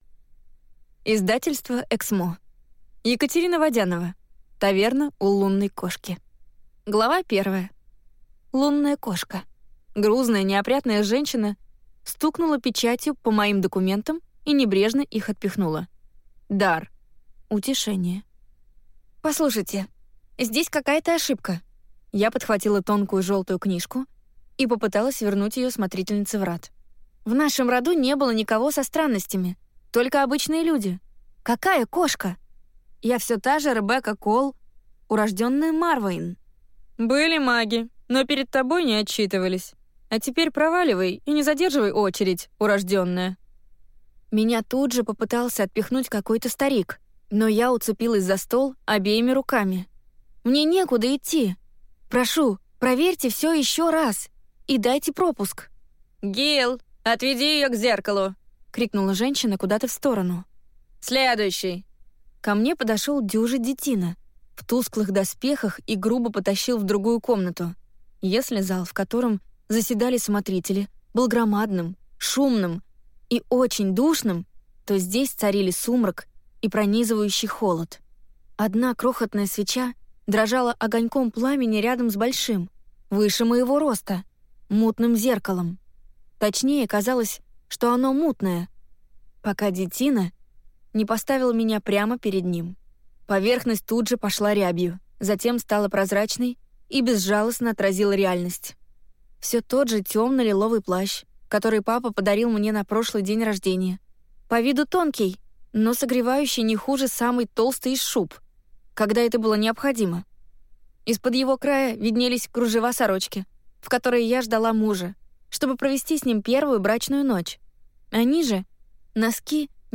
Аудиокнига Таверна «У Лунной кошки» | Библиотека аудиокниг
Прослушать и бесплатно скачать фрагмент аудиокниги